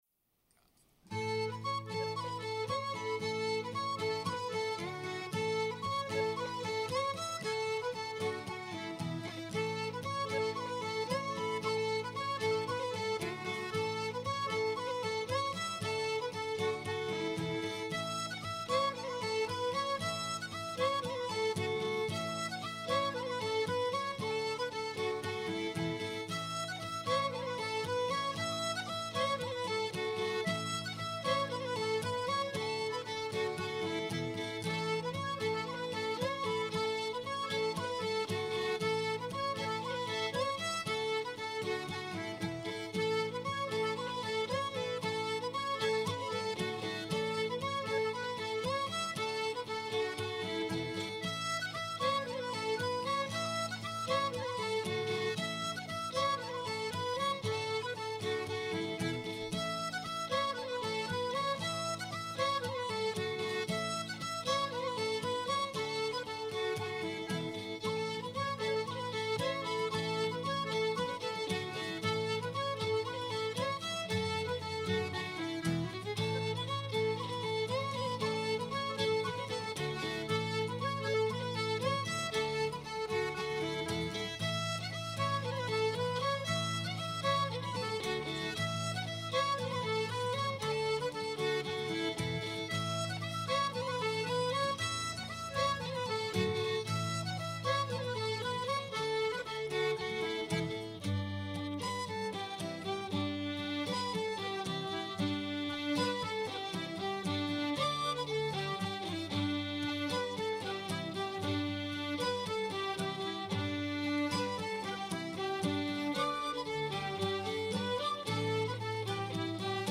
Musiques et danses traditionnelles en Occitanie
Polkas irlandaises
La seconde en Sol majeur ...
accordéon
tin whistle
violon
guitare